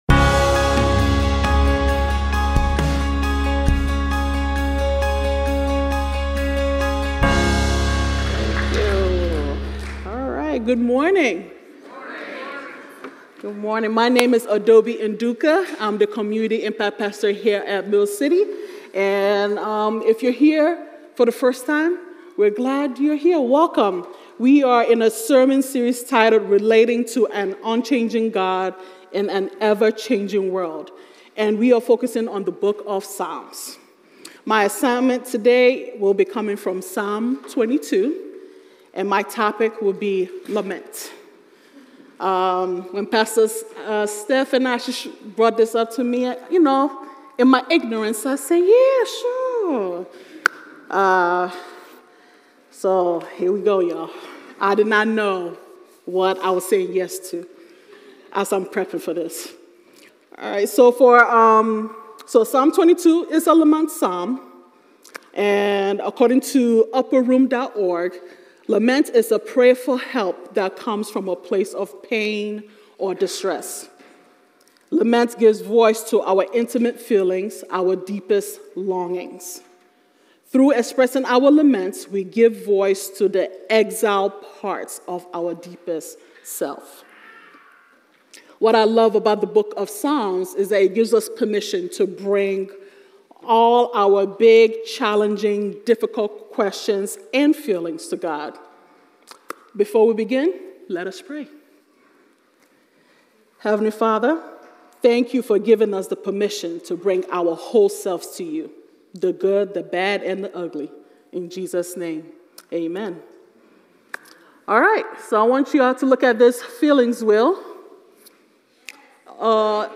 Mill City Church Sermons Relating: Lament is Spiritually Healthy May 06 2024 | 00:29:34 1x 00:00 / 00:29:34 Subscribe Share RSS Feed Share Link Embed